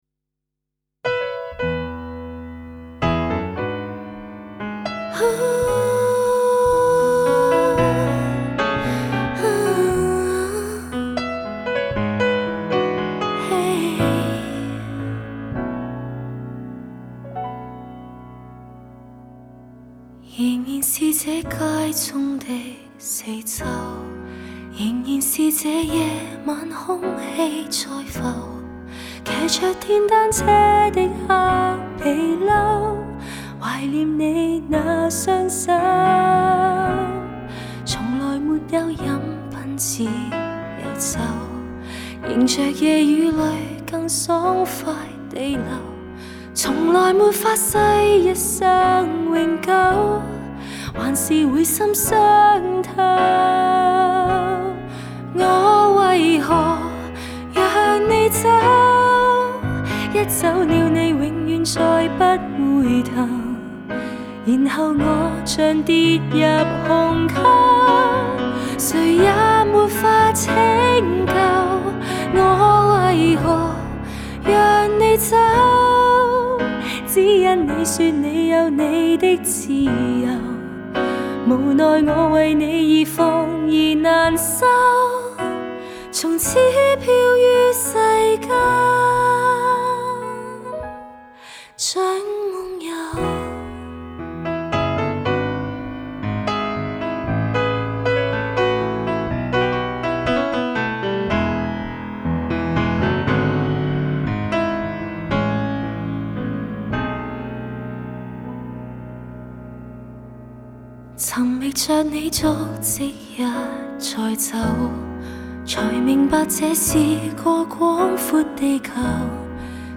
Жанр: Cantopop